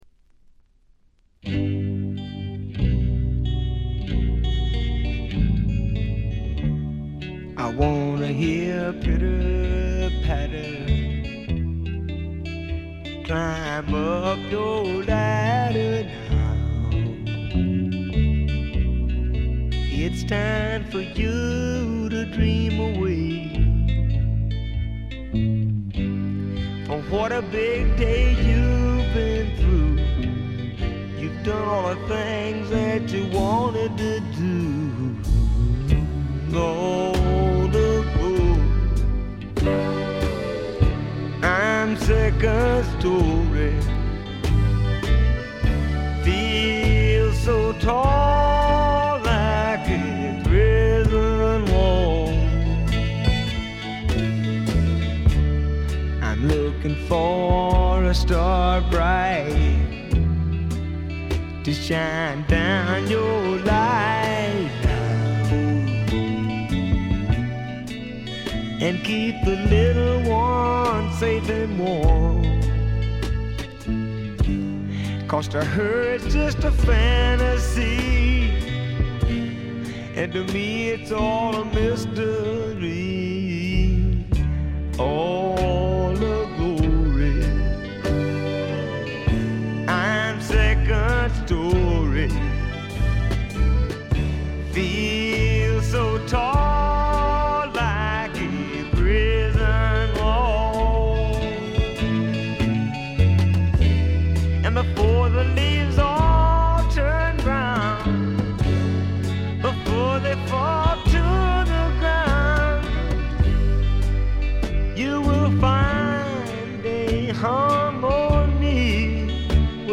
ディスク：部分試聴ですがほとんどノイズ感無し。
試聴曲は現品からの取り込み音源です。